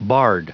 Prononciation du mot bard en anglais (fichier audio)
Prononciation du mot : bard